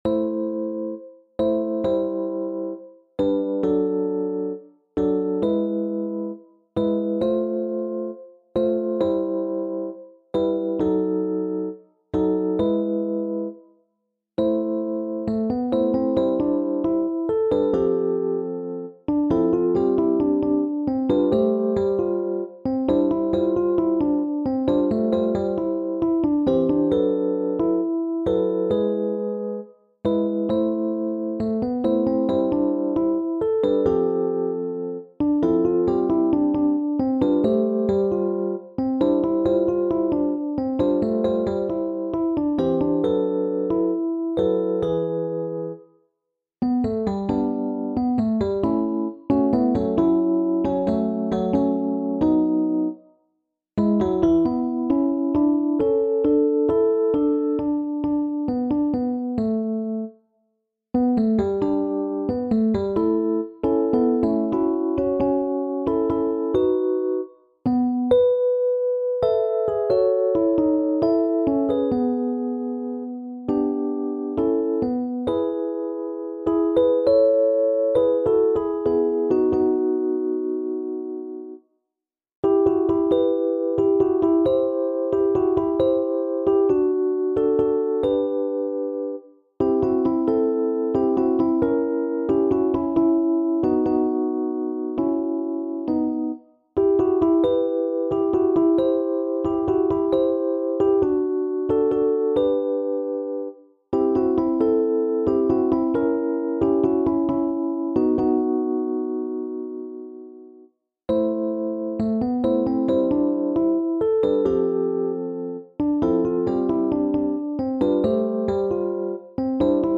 SSAA | SSAB
Ein stimmungsvolles brasilianisches Stück